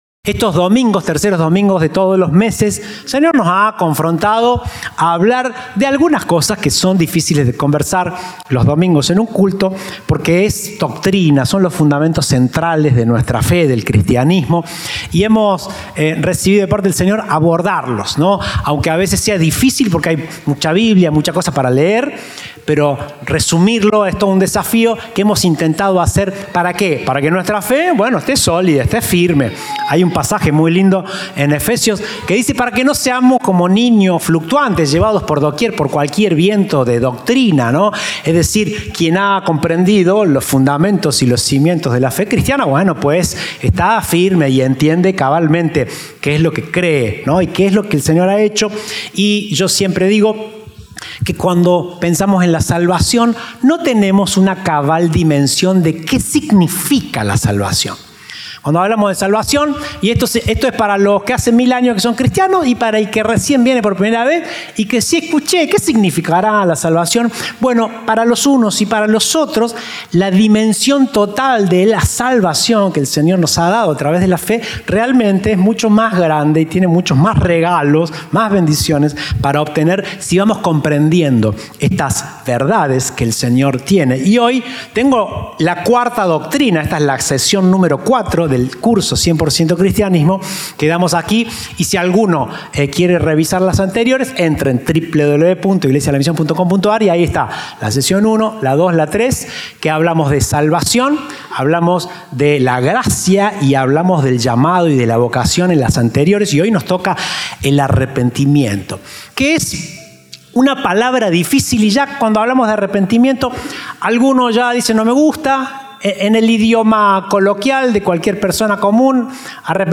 Presentamos esta Serie de mensajes titulada “100% Cristianismo”.